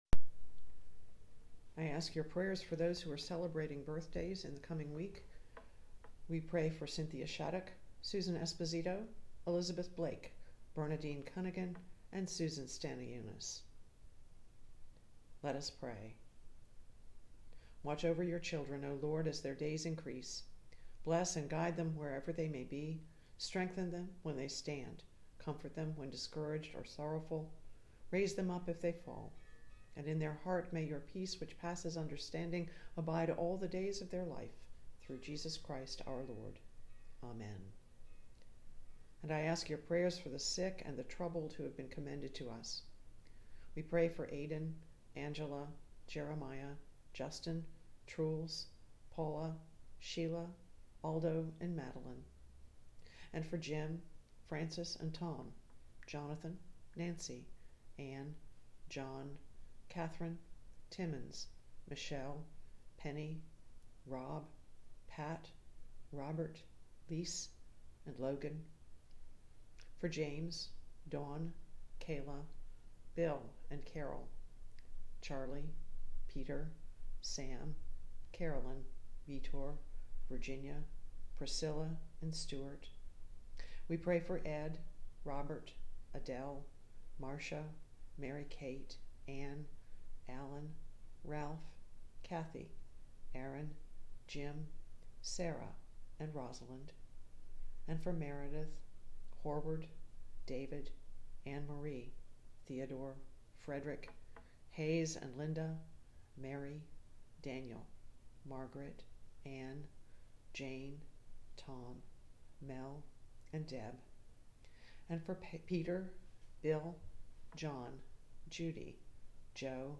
Recording of Weekly Prayers:
Prayer-list-1_5_25.m4a